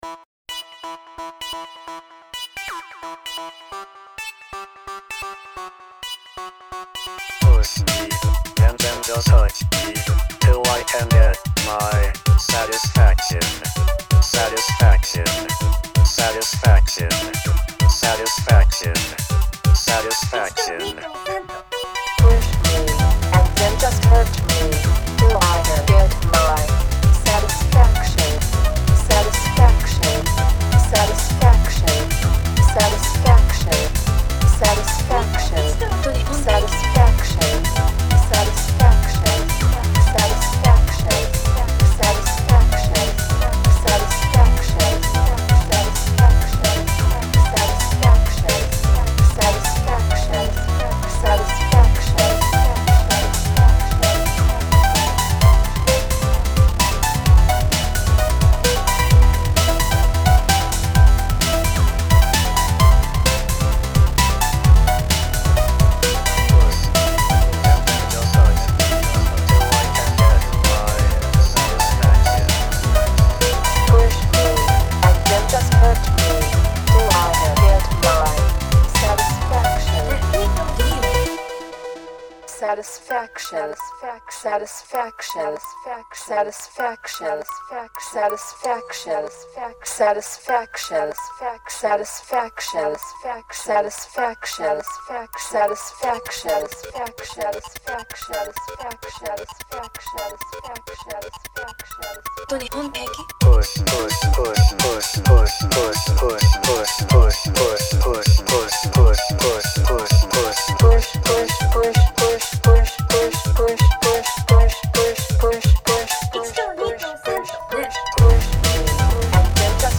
Ремикс